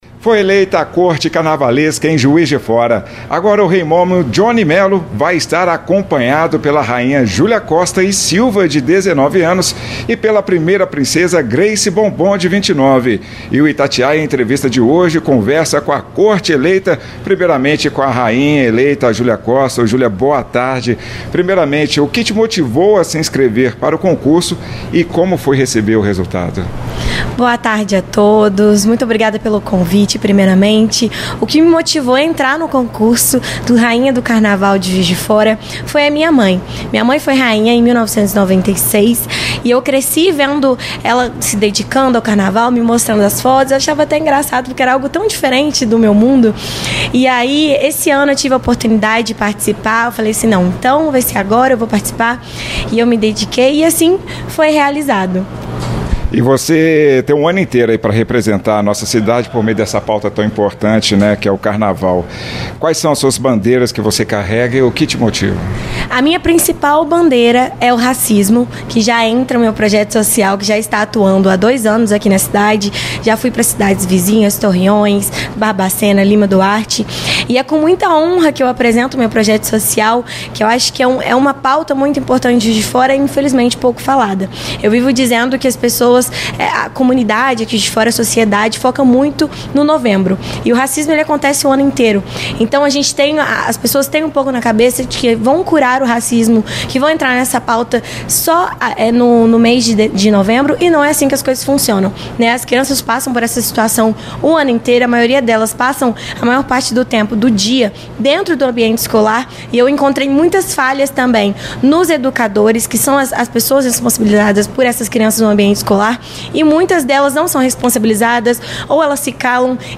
Itatiaia-Entrevista-Corte-Real-do-Carnaval-2024-Juiz-de-Fora.mp3